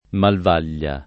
[ malv # l’l’a ]